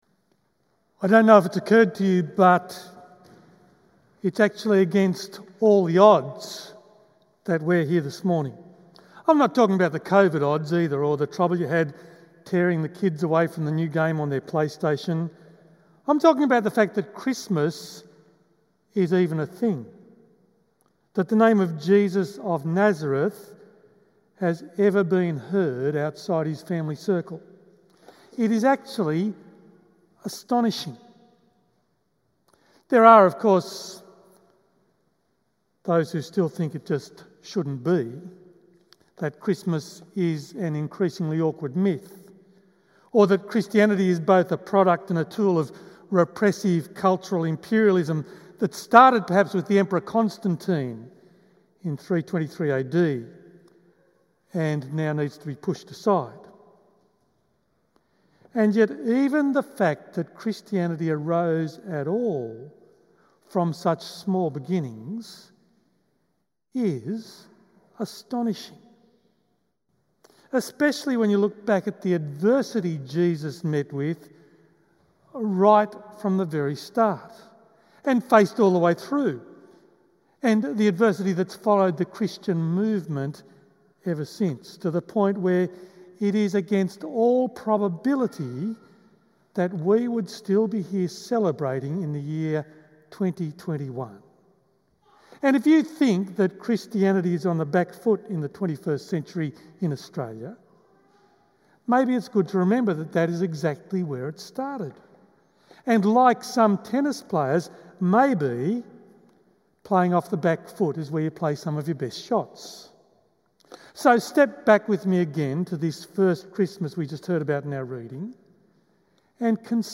Full Service Audio